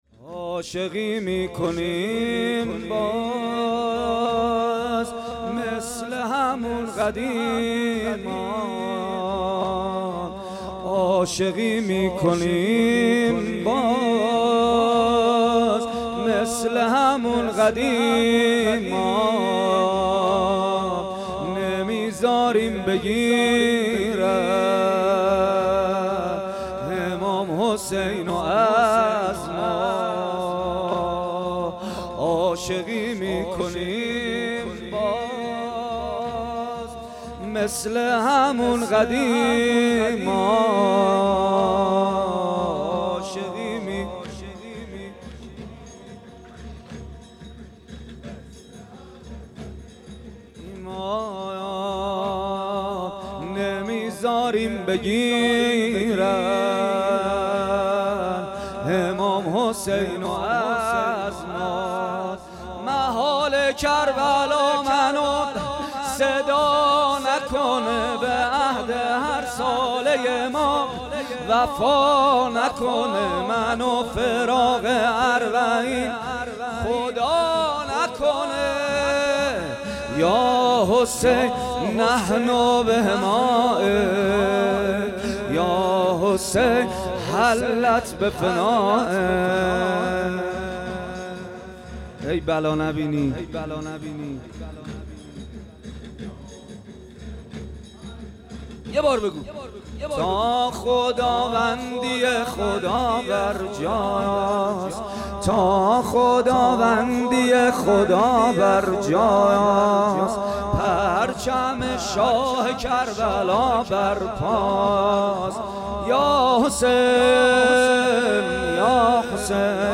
عزاداری شب هشتم محرم 1399با نوای محمدحسین پویانفر